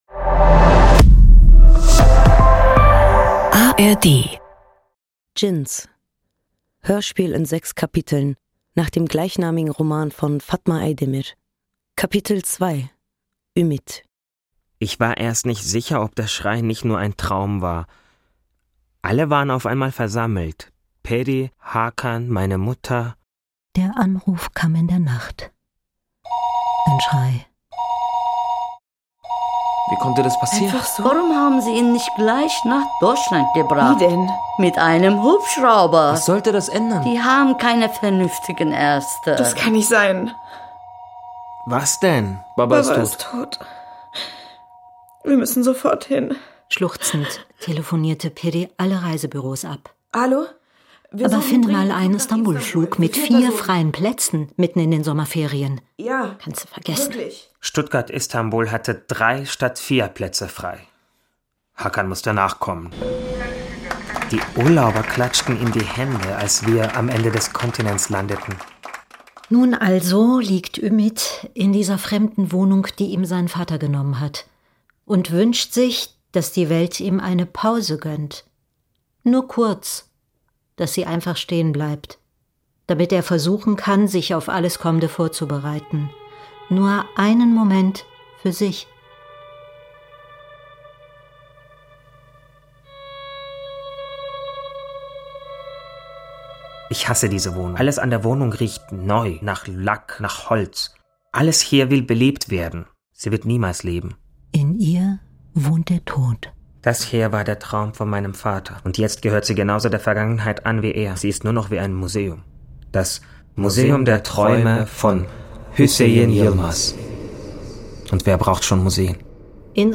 Basierend auf einer Theaterfassung von Selen Kara.
Hörspieleinrichtung und Regie